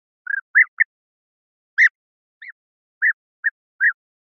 Звуки золотистой щурки
Чистый звук